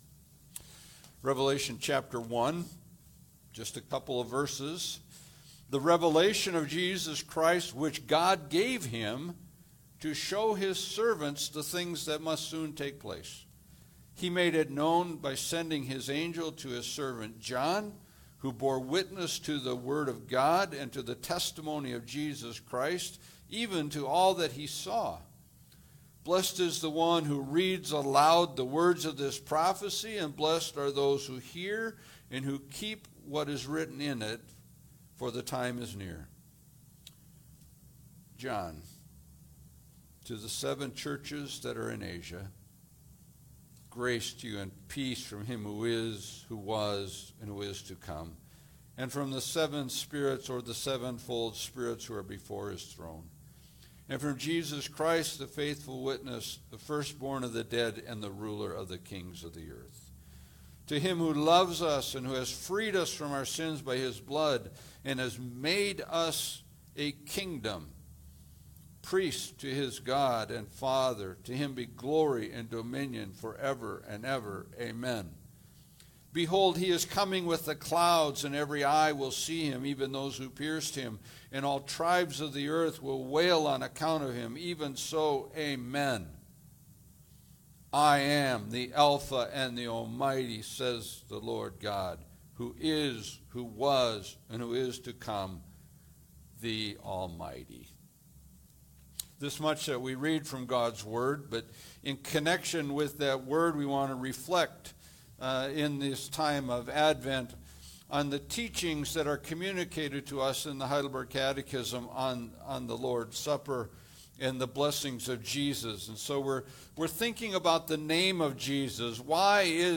Passage: Revelation 1:1-8 Service Type: Sunday Service